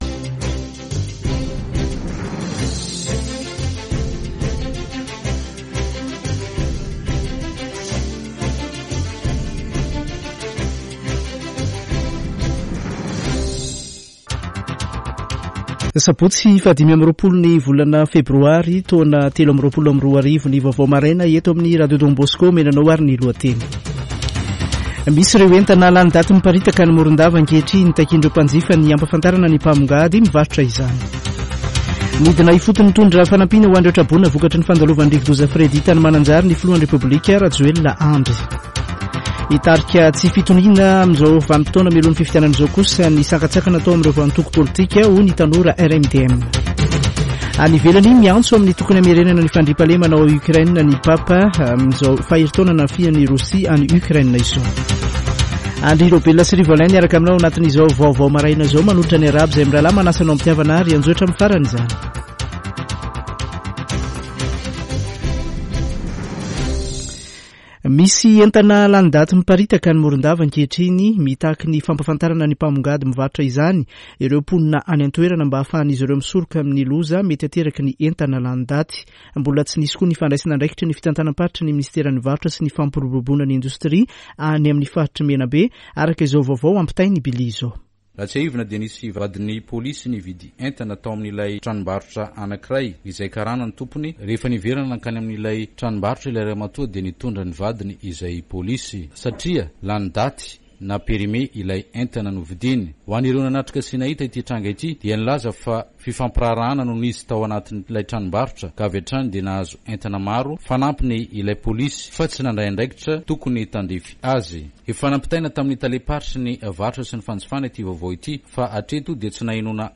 [Vaovao maraina] Sabotsy 25 febroary 2023